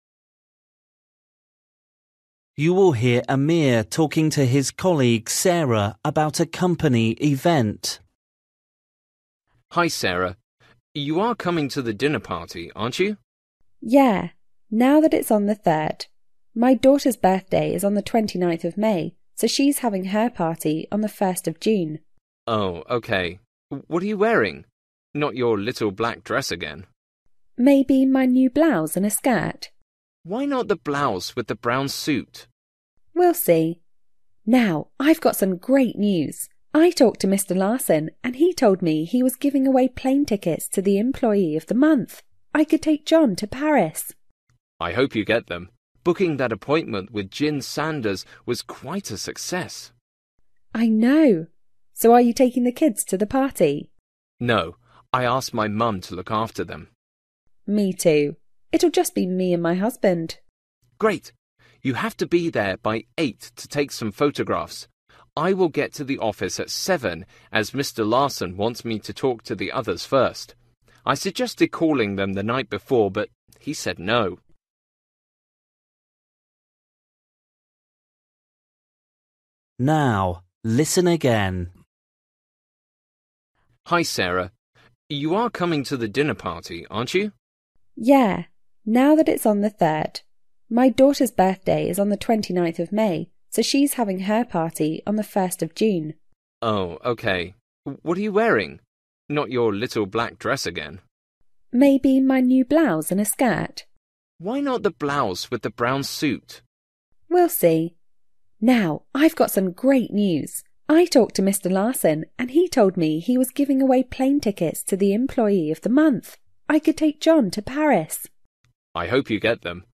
Bài tập trắc nghiệm luyện nghe tiếng Anh trình độ sơ trung cấp – Nghe một cuộc trò chuyện dài phần 26